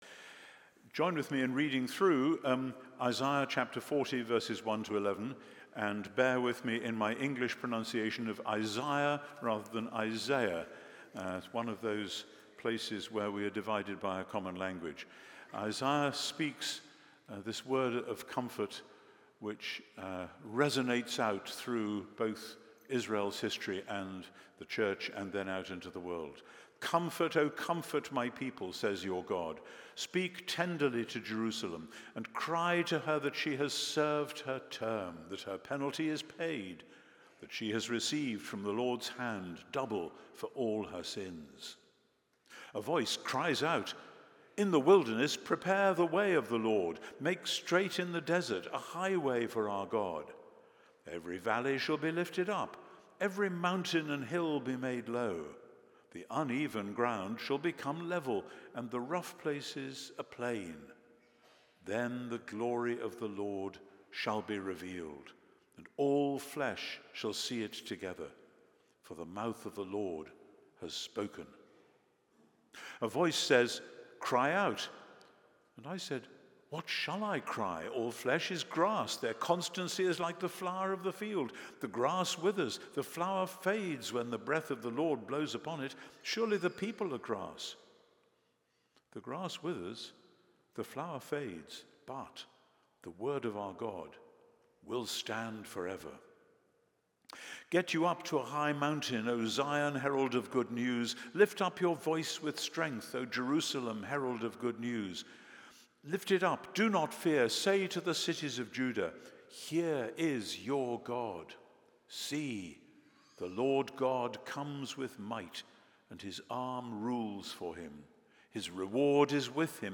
Standalone Sermon: The God of All Comfort